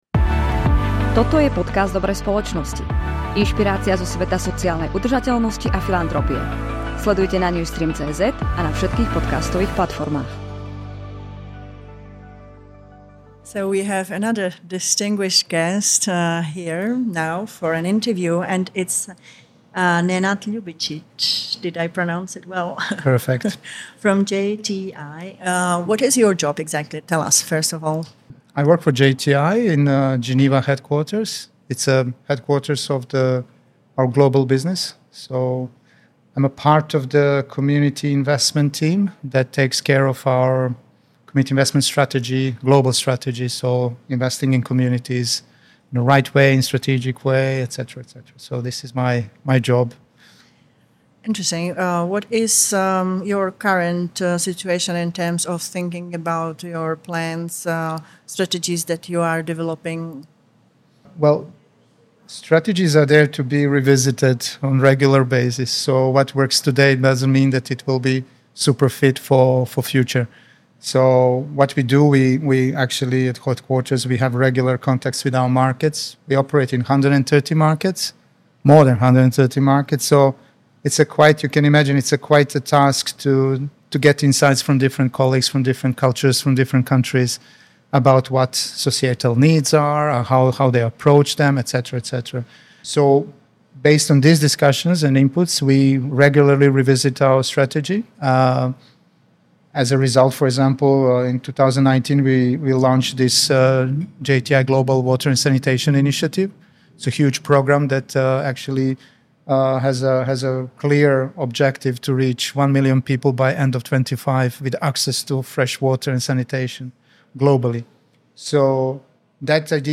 rozhovoru